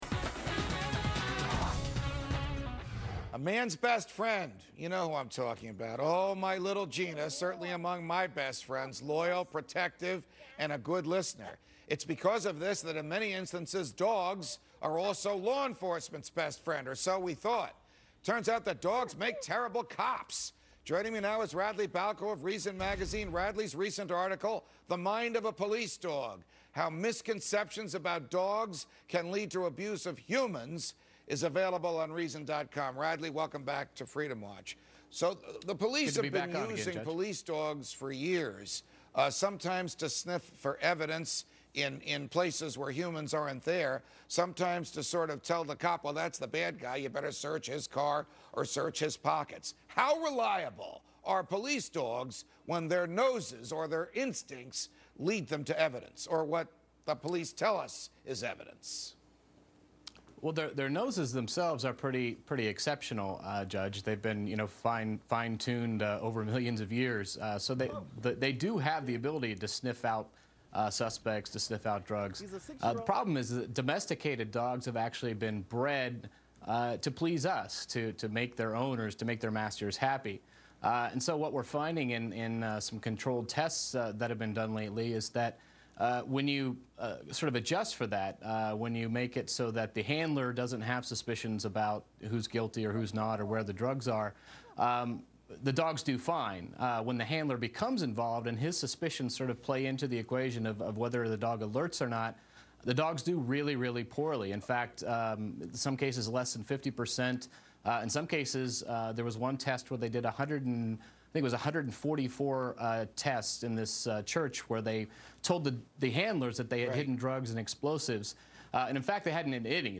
Reason Senior Editor Radley Balko appeared on Fox Business' Freedom Watch With Judge Napolitano to discuss how the fallibility of police dogs often leads to wrongful prosecution.